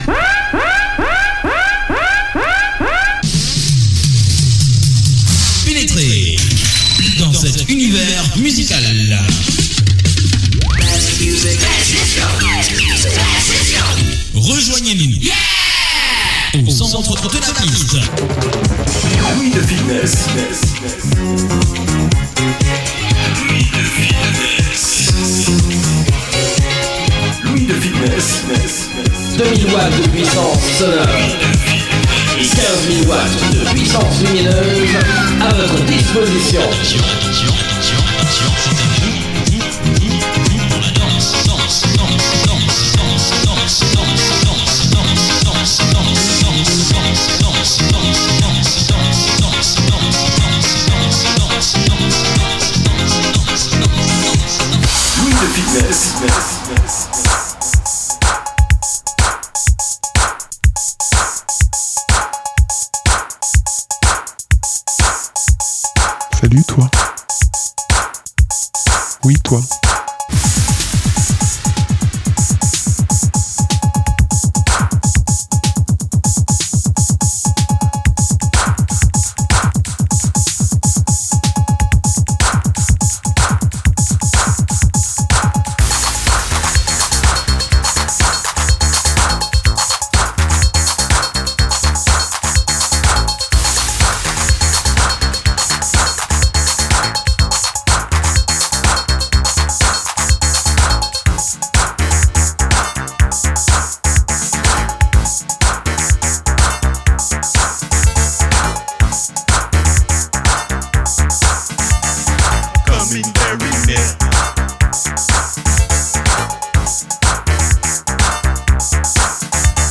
L’OUIE DE FITNESS – S02 #43 | House 80’s
Une sélection 100% fanfare